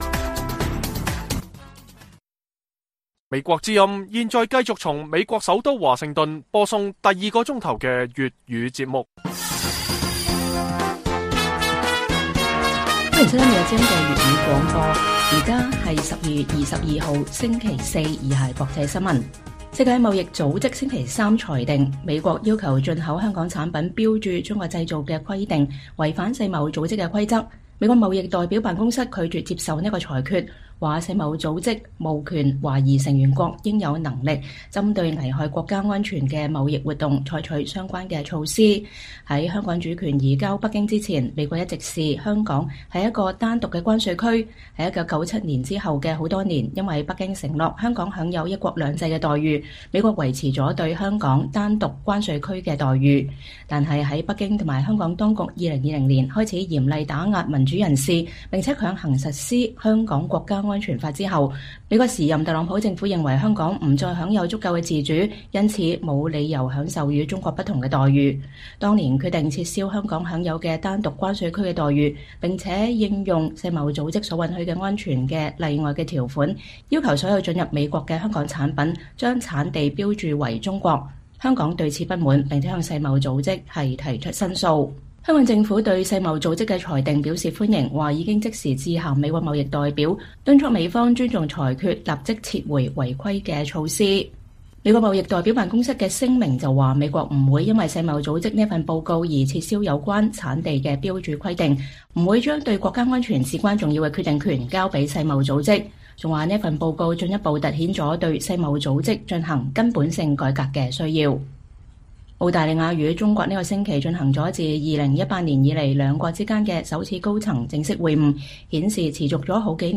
粵語新聞 晚上10-11點 : 台灣立法院長游錫堃將訪華盛頓參加祈禱早餐會並在國際宗教自由峰會發表演說